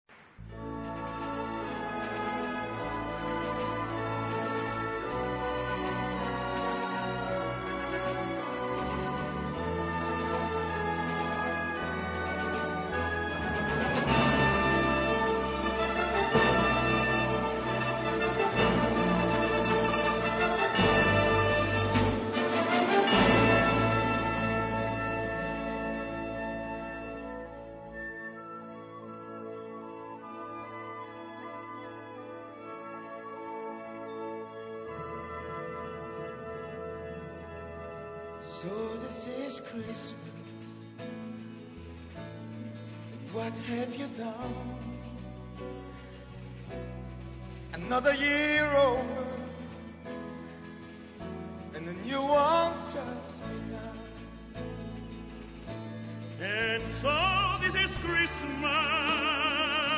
key: A-major